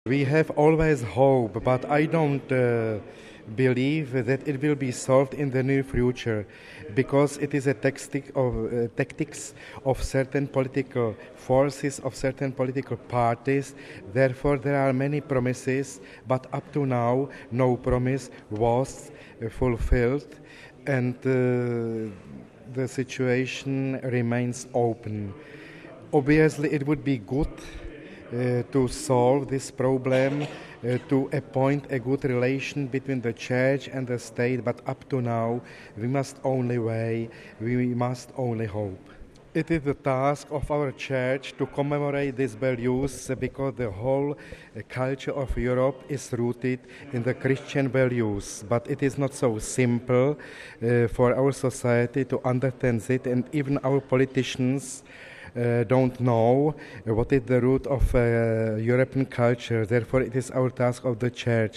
(18 Nov 05 - RV) Pope Benedict XVI met with members of the Czech Republic's Bishops Conference today at the conclusion of the Ad Limina Visit. Following the audience, Vaclav Mali, Auxiliary Bishop of Prague, spoke with us...